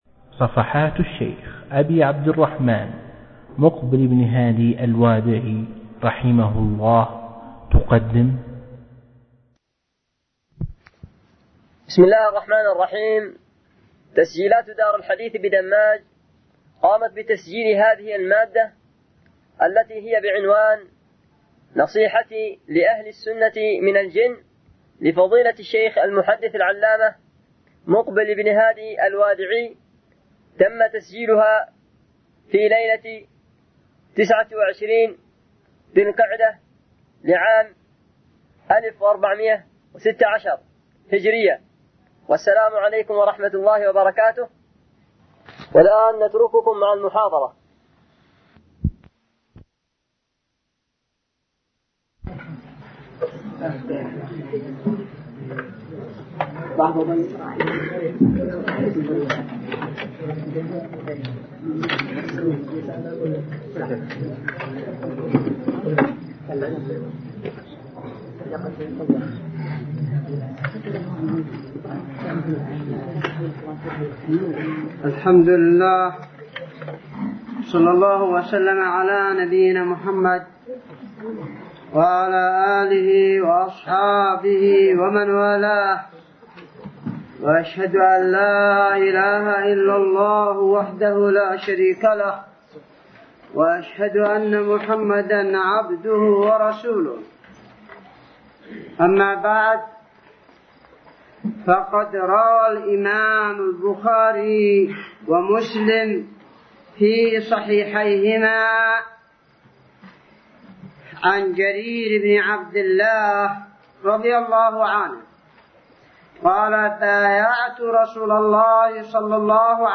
محاضرة نصيحتي لأهل السنة من الجن الشيخ مقبل بن هادي الوادعي